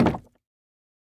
Minecraft Version Minecraft Version latest Latest Release | Latest Snapshot latest / assets / minecraft / sounds / block / bamboo_wood_fence / toggle2.ogg Compare With Compare With Latest Release | Latest Snapshot